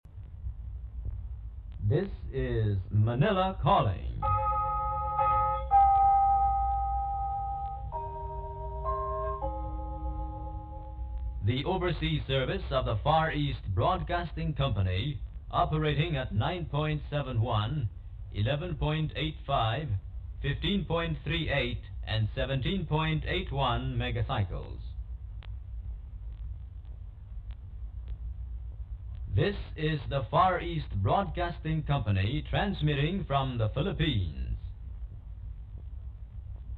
Station ID Audio